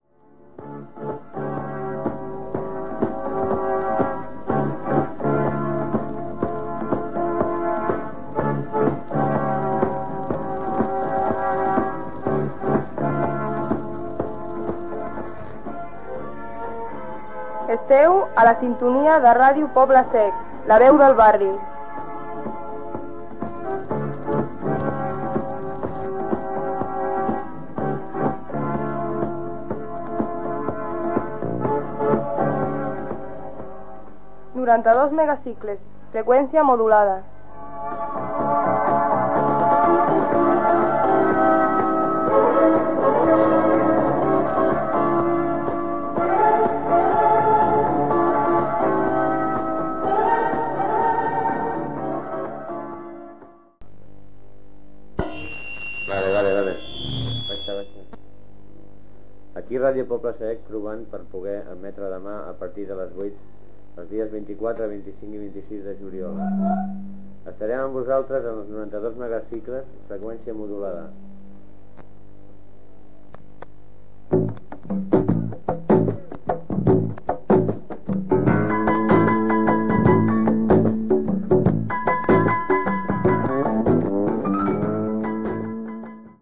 Proves d'emissió i anunci d'inici de la programació el 24 juliol de 1981.
FM